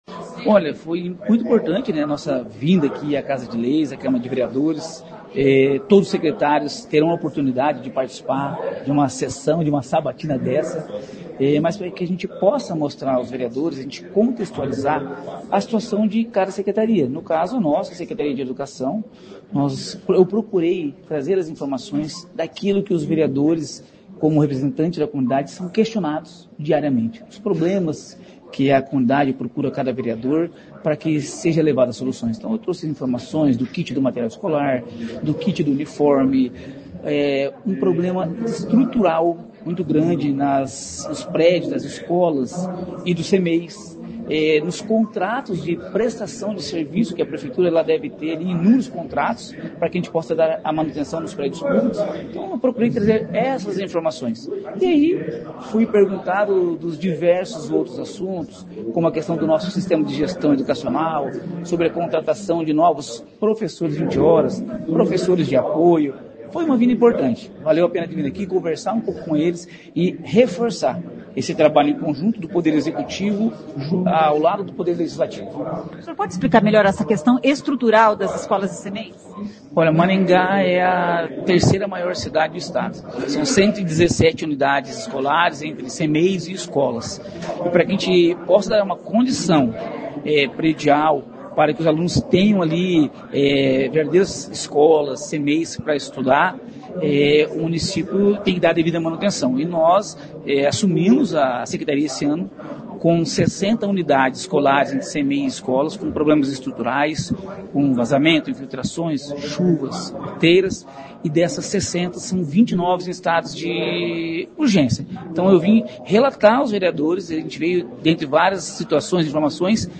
O secretário de Educação de Maringá, Fernando Brambilla, foi à Câmara Municipal nesta quinta-feira (13) para falar sobre as ações da secretaria e para responder perguntas de vereadores.
Ouça o que disse o secretário: